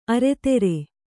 ♪ areteṛe